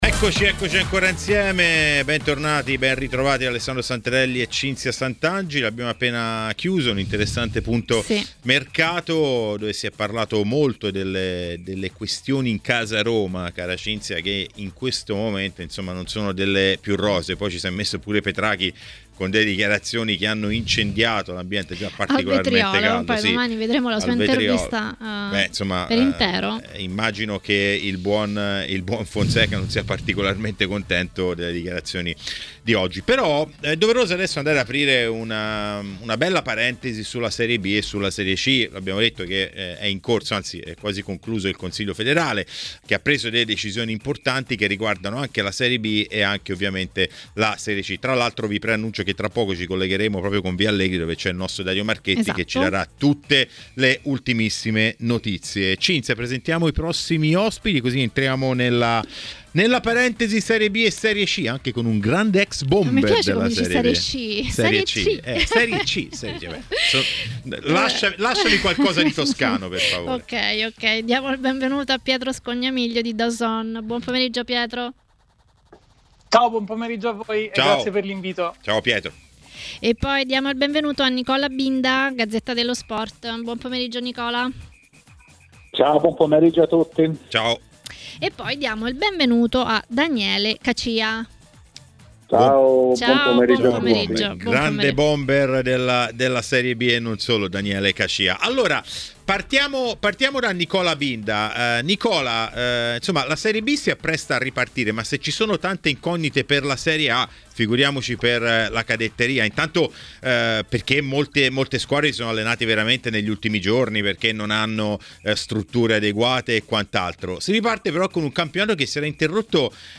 L'attaccante Daniele Cacia è intervenuto a TMW Radio, durante Maracanà, per parlare di Serie B. Ecco le sue parole: